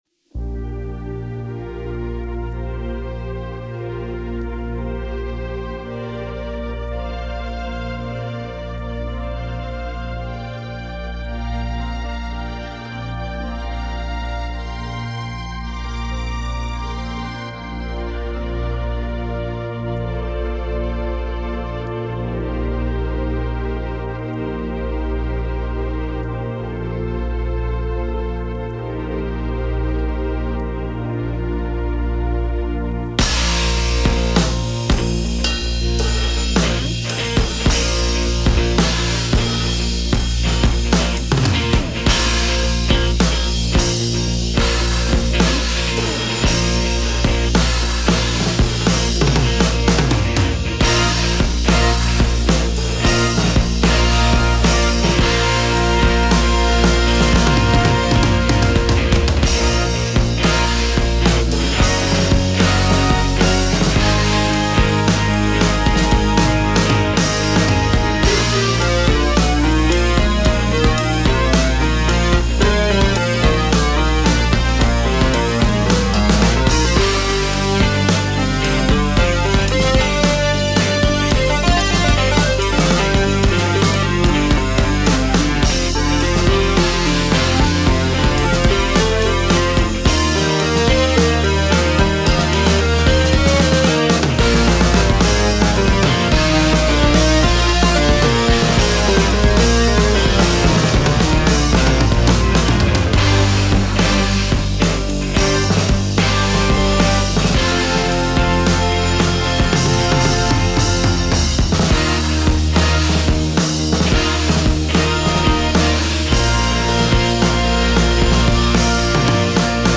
In dieser Spezial-Folge wird nicht gesprochen!
wie sich seine Energie am Schlagzeug mit der Musik entfaltet.